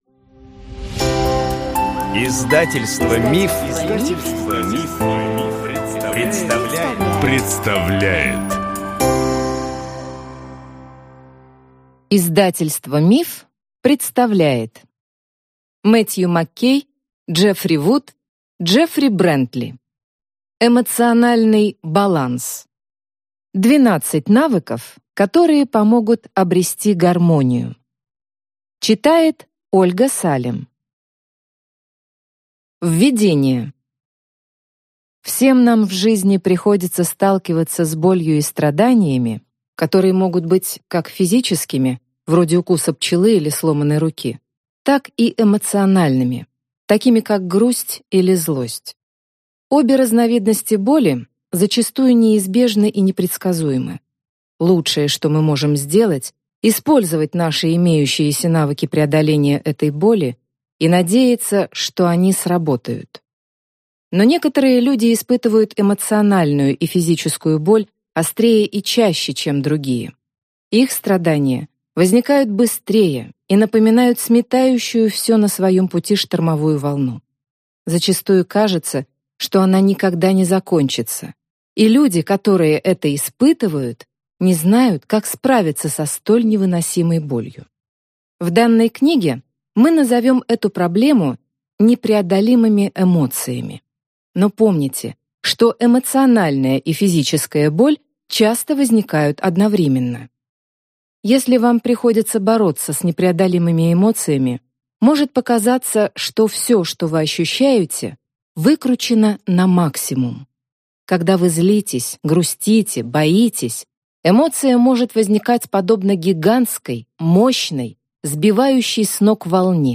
Аудиокнига Эмоциональный баланс. 12 навыков, которые помогут обрести гармонию | Библиотека аудиокниг